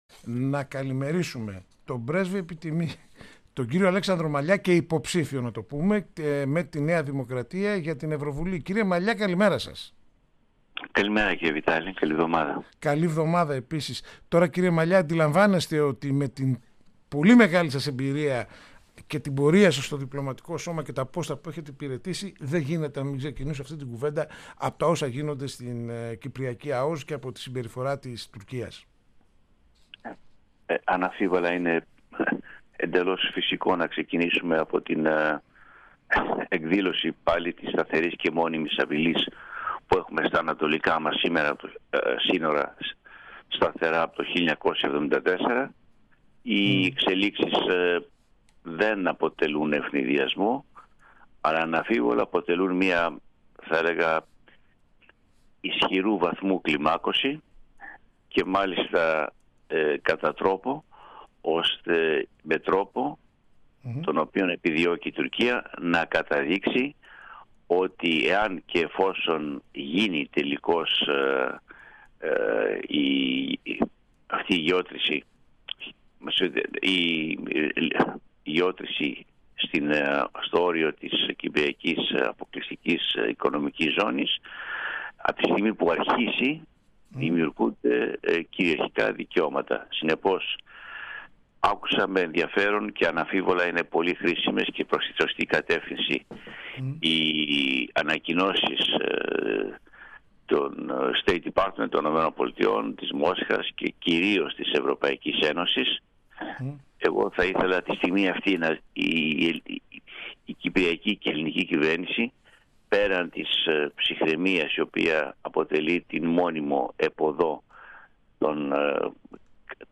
Συζήτηση στο Πρώτο Πρόγραμμα